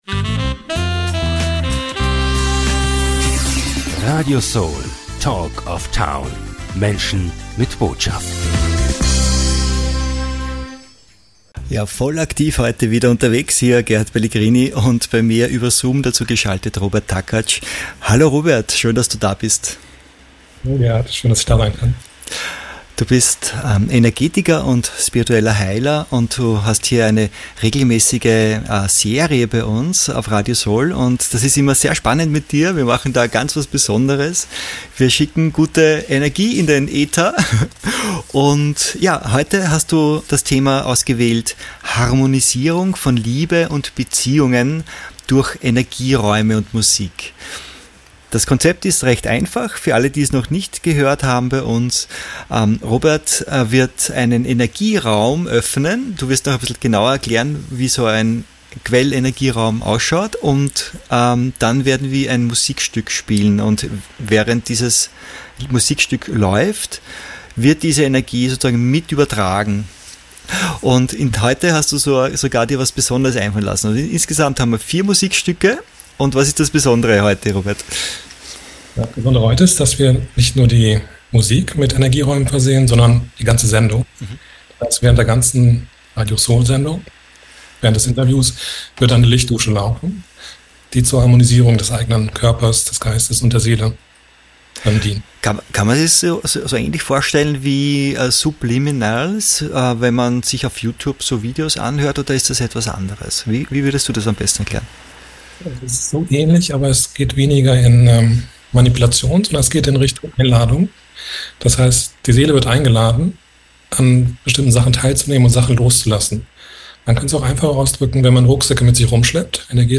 Er spricht in diesem Interview unter anderem über die Harmonisierung der Beziehungen zu den Mitmenschen, Lichtdusche und über die Intelligenz von Energiearbeit mit Quellenergie.